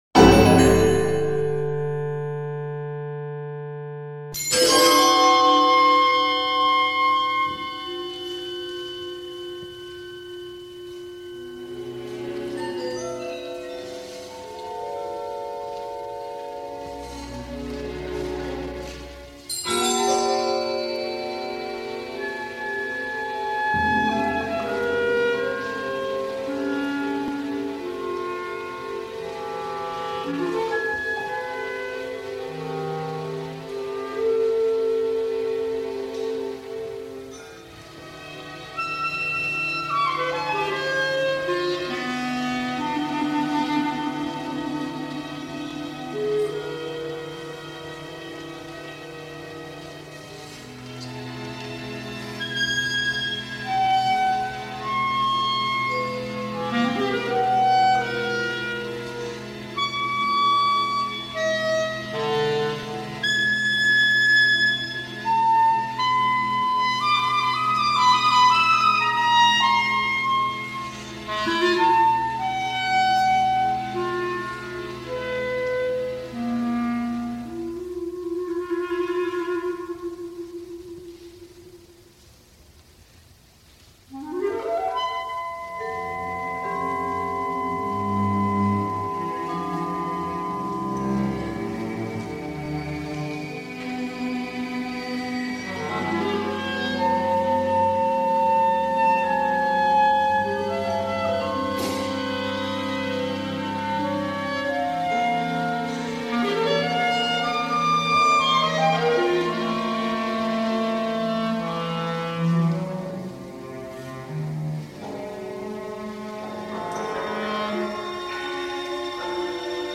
solo clarinet, strings and MIDI sounds